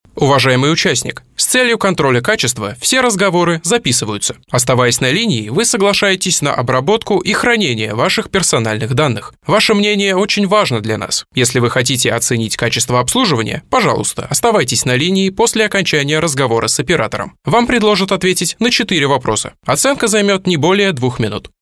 Владею широким диапазоном эмоций - все для вас, как говорится.
Blue Yeti